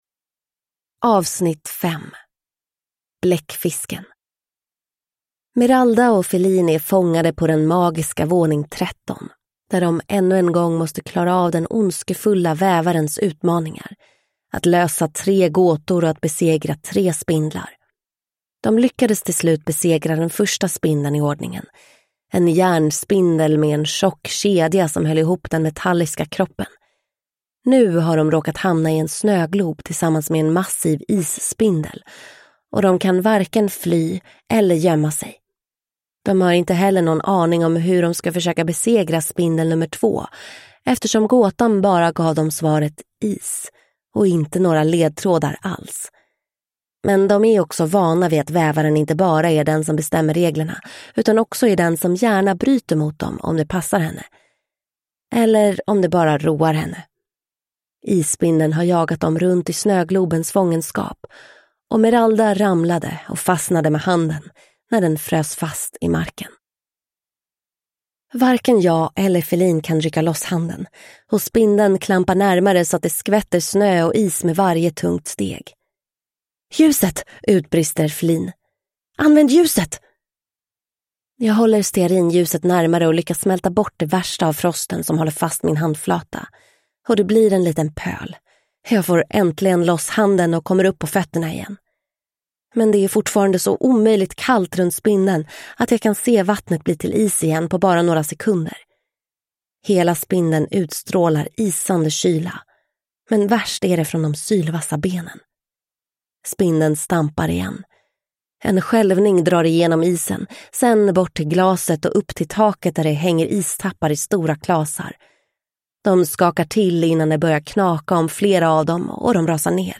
Bläckfisken (S2E5 Spindelhuset) – Ljudbok